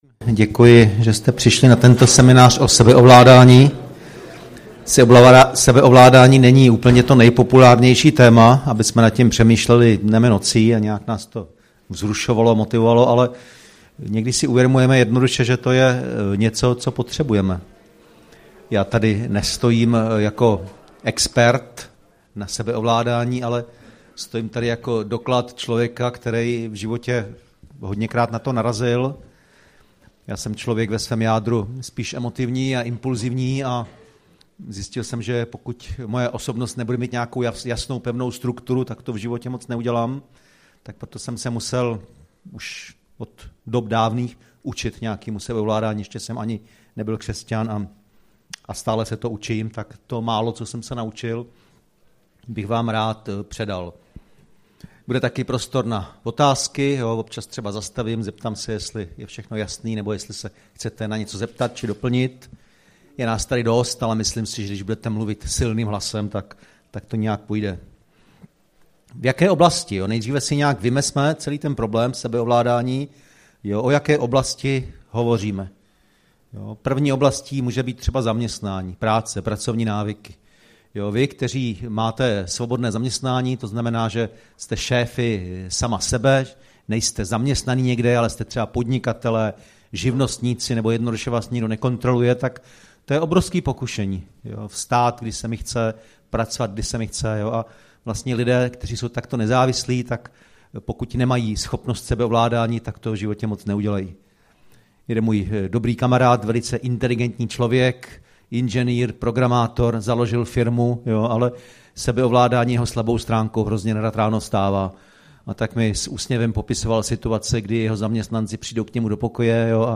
Konference pro muže - Limity 2018, 17. 3., Brno